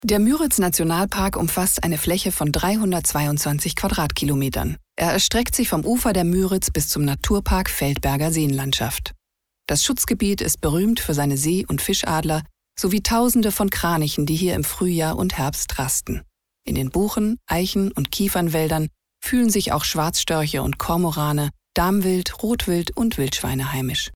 sehr variabel
Mittel plus (35-65)
Doku, Off